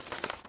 x_enchanting_scroll.2.ogg